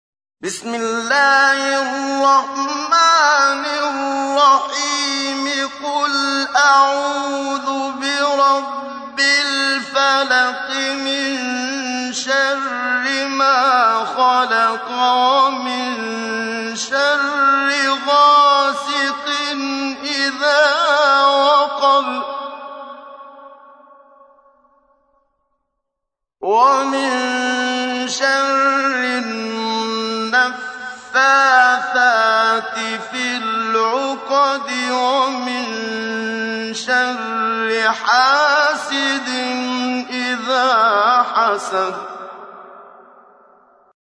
تحميل : 113. سورة الفلق / القارئ محمد صديق المنشاوي / القرآن الكريم / موقع يا حسين